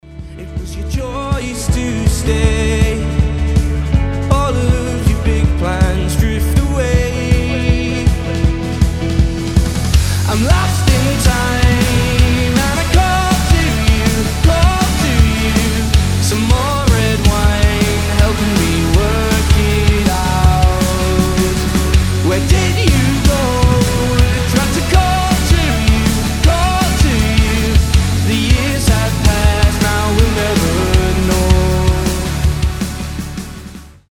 • Качество: 320, Stereo
красивый мужской голос